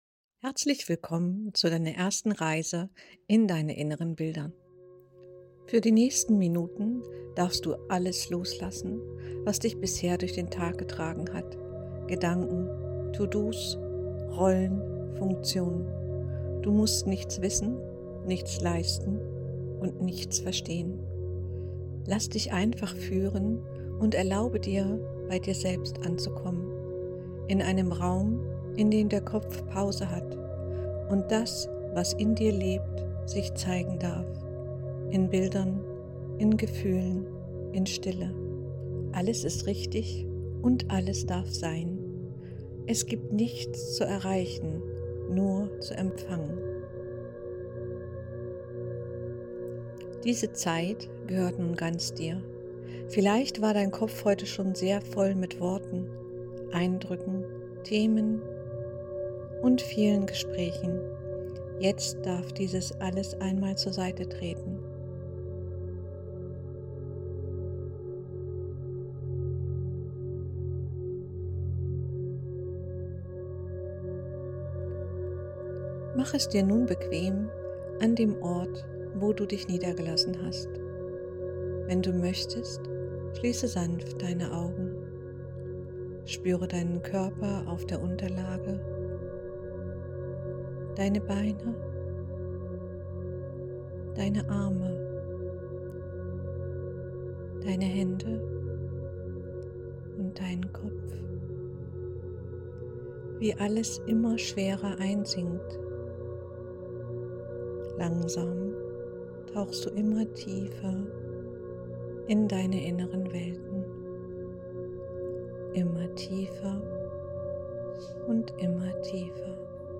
Diese sanfte, geführte Audioreise lädt dich ein, deinem inneren Ausdruck liebevoll zu begegnen – auch wenn du noch nicht weißt, was gesagt werden will.